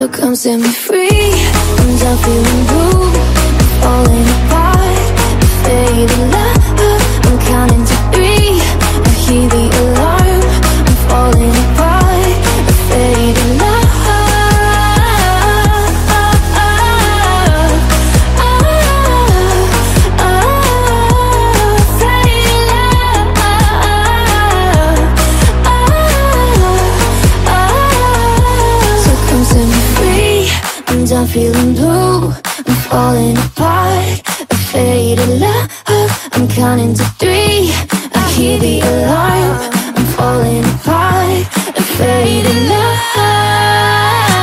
Kategorien: POP